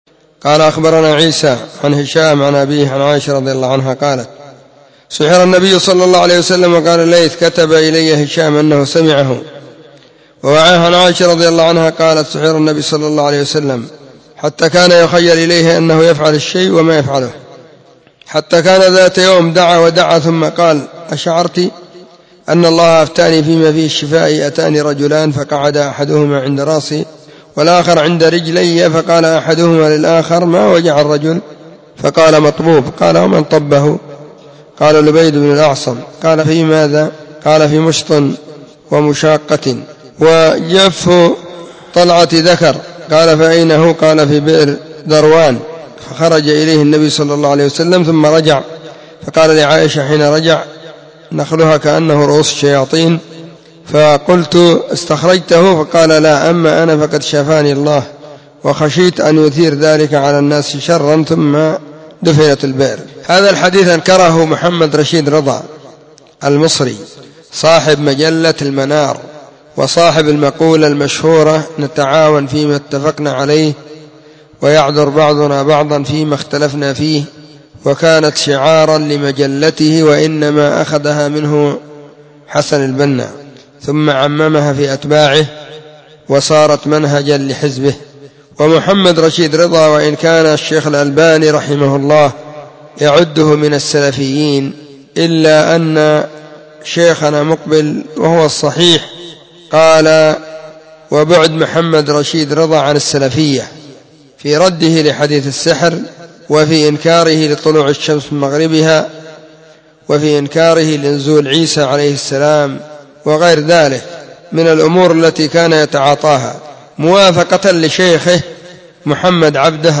📢 مسجد الصحابة – بالغيضة – المهرة – اليمن حرسها الله.
الثلاثاء 23 محرم 1443 هــــ | الردود الصوتية | شارك بتعليقك